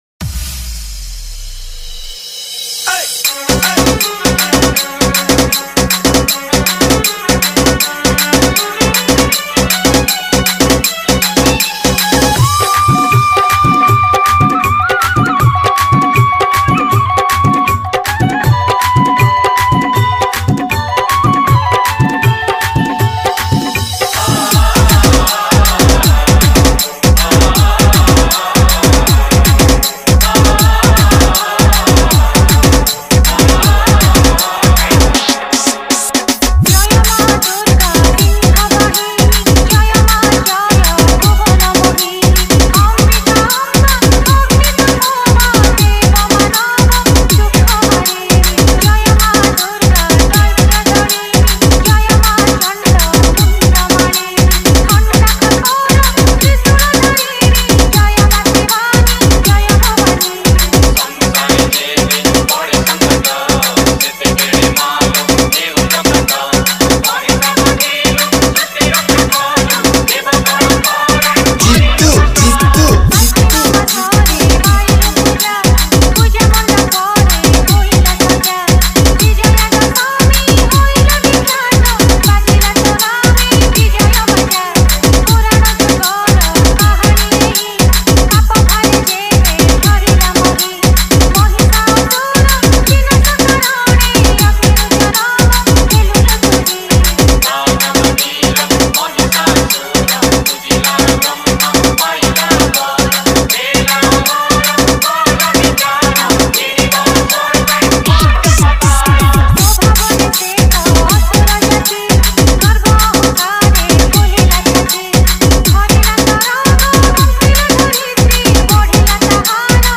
odia dj mp3
Odia Bhajan Dj